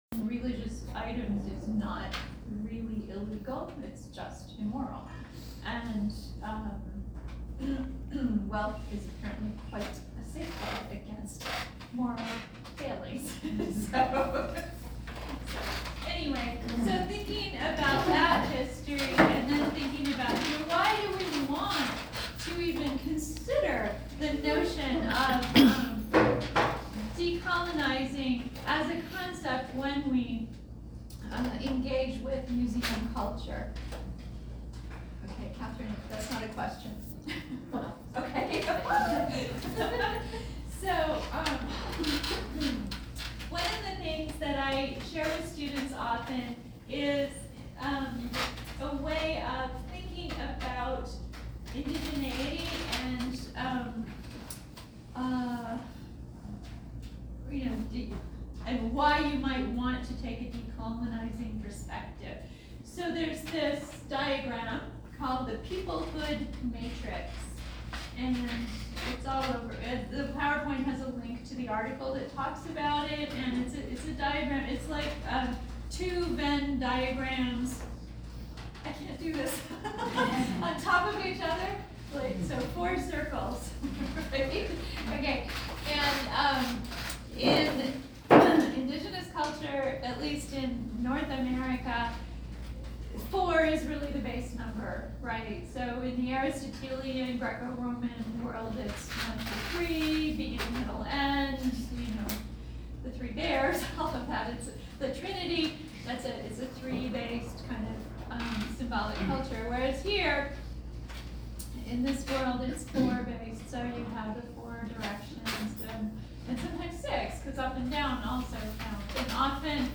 Live sound recording at Tubac - Lecture
lecture-from-Lunch-6-22-22.mp3